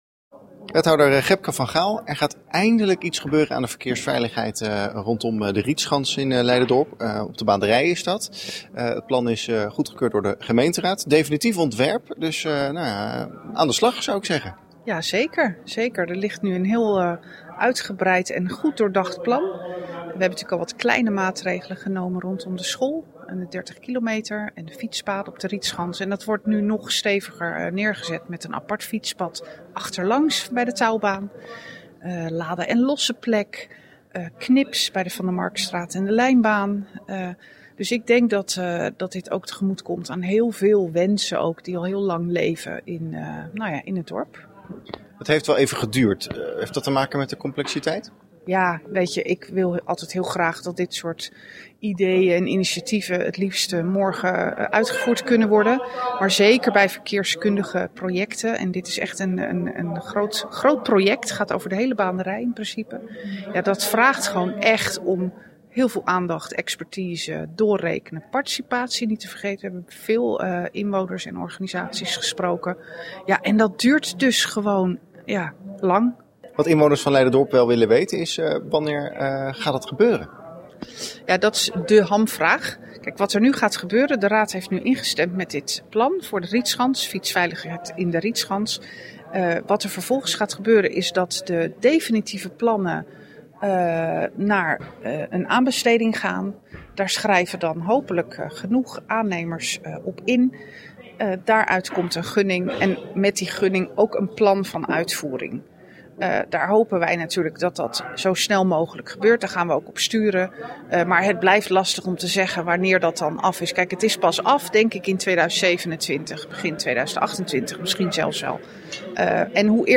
Wethouder Gebke van Gaal over de plannen voor De Baanderij
Wethouder-Gebke-van-Gaal-over-de-plannen-voor-De-Baanderij.mp3